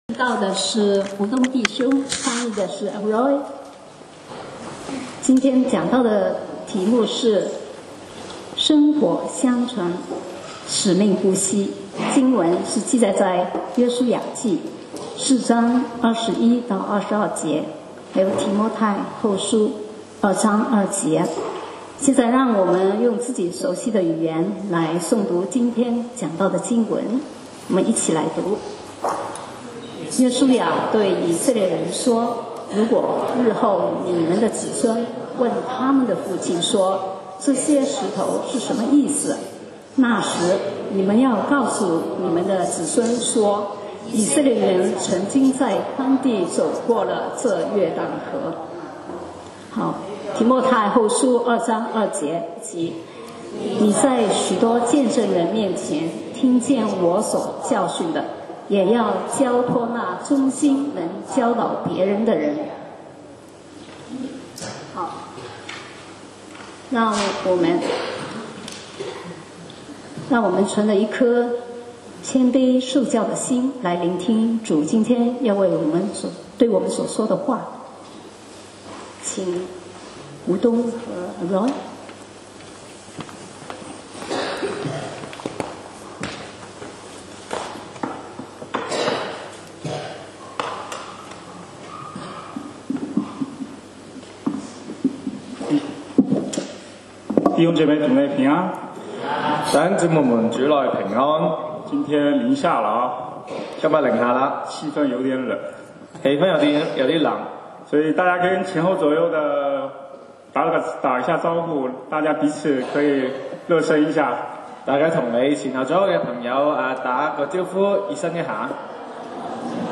講道 Sermon 題目 Topic：薪火相传，使命不熄 經文 Verses：约书亚记4:21-22， 提摩太后书 2:2. 21对以色列人说，日后你们的子孙问他们的父亲说，这些石头是什么意思。